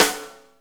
Snares
SW SNR3.wav